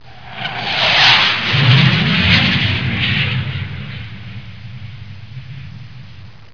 دانلود آهنگ طیاره 42 از افکت صوتی حمل و نقل
دانلود صدای طیاره 42 از ساعد نیوز با لینک مستقیم و کیفیت بالا
جلوه های صوتی